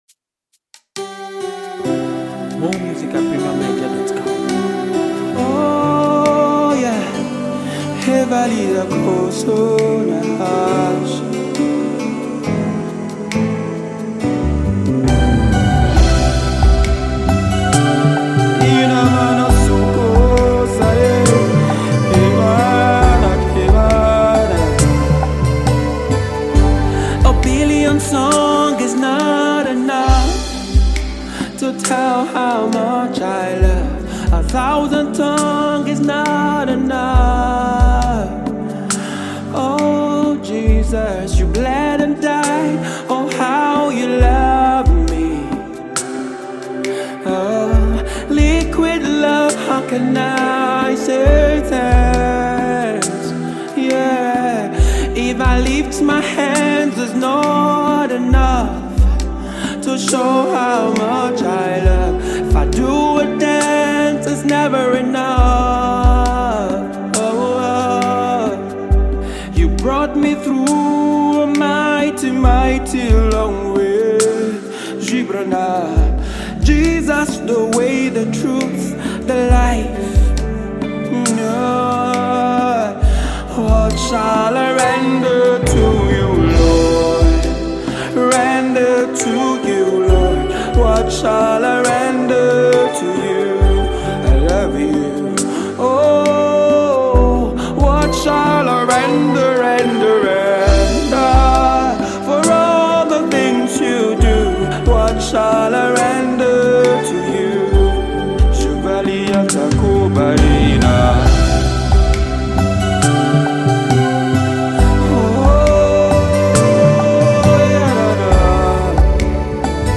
The Nigerian gospel-rational singer and songwriter
worship anthem